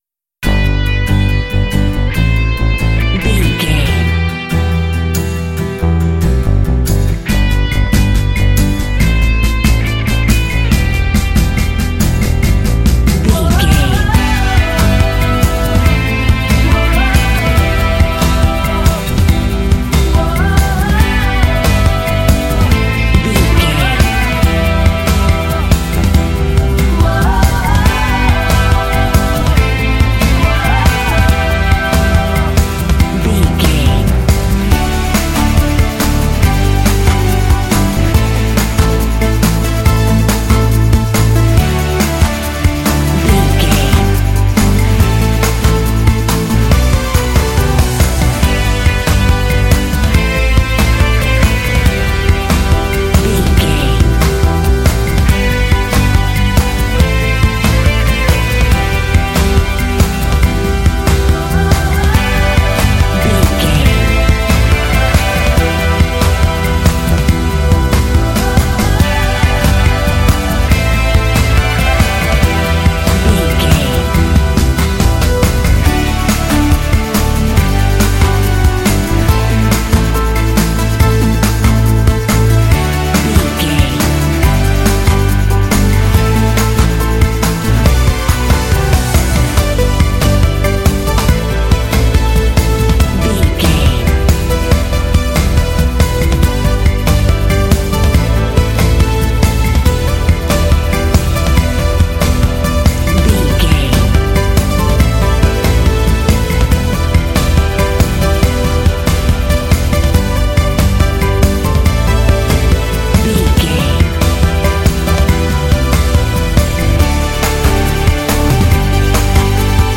Uplifting
Ionian/Major
Fast
confident
energetic
acoustic guitar
electric guitar
bass guitar
drums
piano
synthesiser
strings
vocals
rock